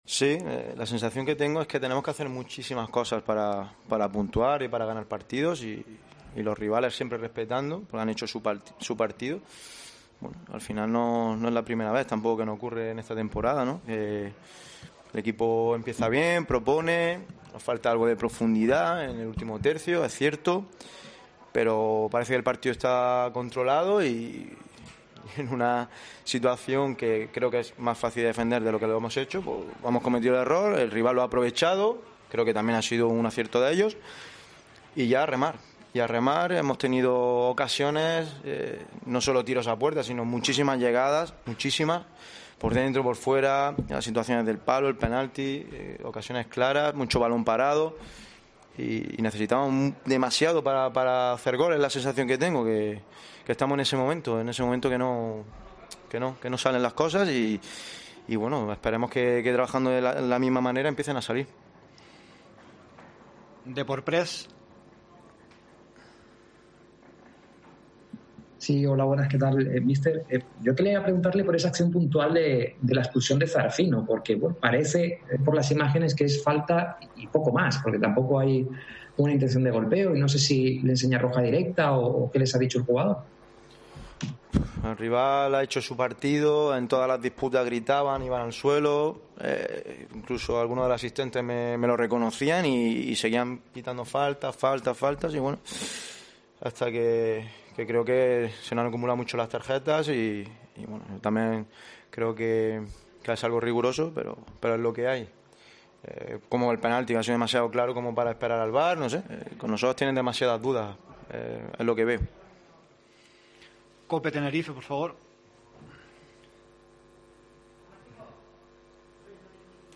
AUDIO: Escucha aquí el postpartido con declaraciones de Fran Fernández, míster del Tenerife, y Jon Pérez Bolo, entrenador de la Deportiva Ponferradina